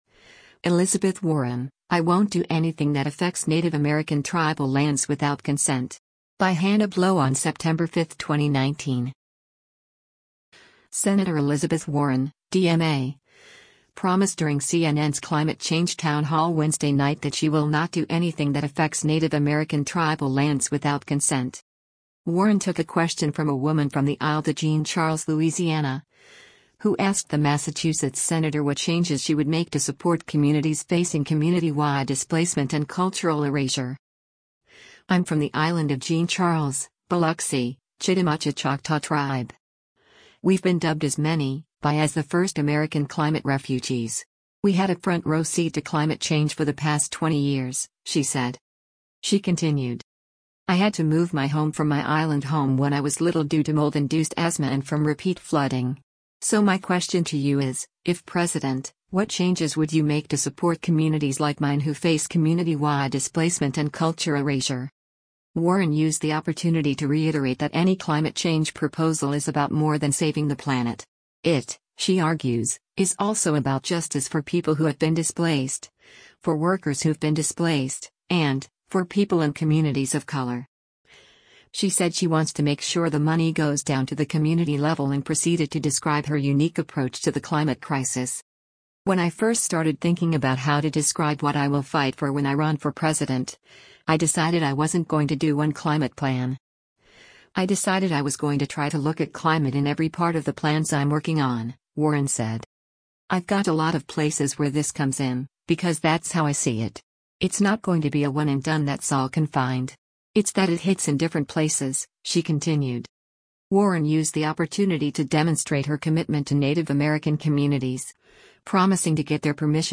Sen. Elizabeth Warren (D-MA) promised during CNN’s climate change town hall Wednesday night that she will not do anything that affects Native American tribal lands without consent.
Warren took a question from a woman from the Isle de Jean Charles, Louisiana, who asked the Massachusetts senator what changes she would make to “support communities facing community-wide displacement and cultural erasure.”